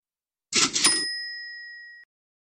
Cash Register
Cash-Register-.mp3